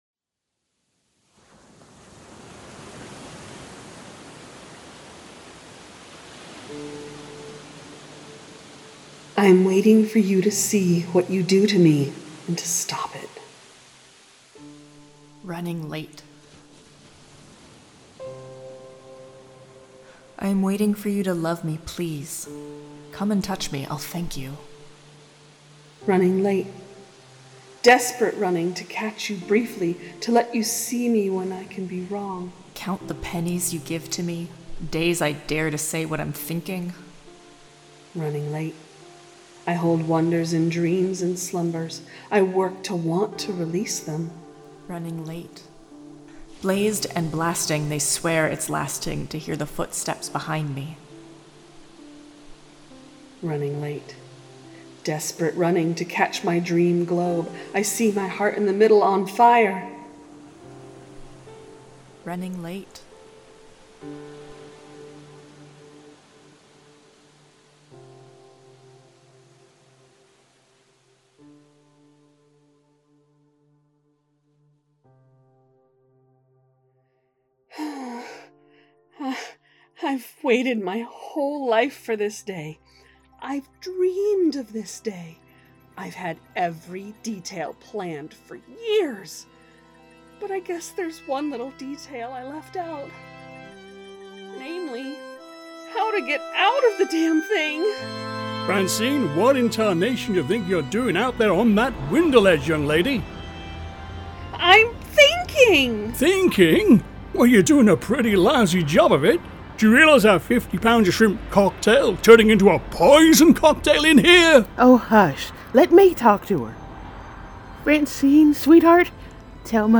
The Ocadecagonagon Theater Group
strangers-in-paradise-the-audio-drama-book-8-my-other-life-episode-2